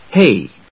/héɪ(米国英語), heɪ(英国英語)/